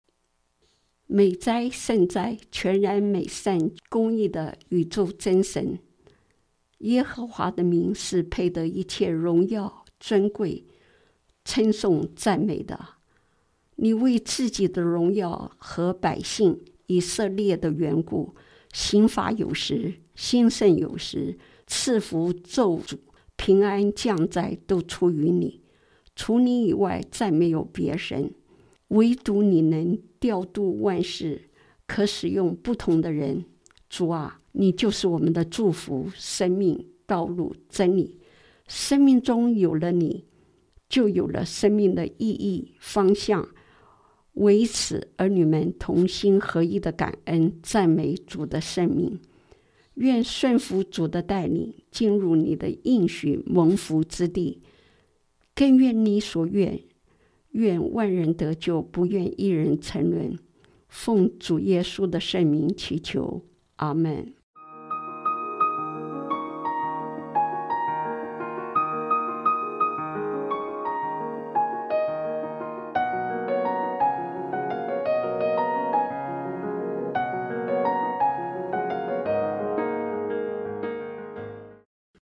今日祈祷– 进入祢的应许蒙福之地 – 生命之光广播电台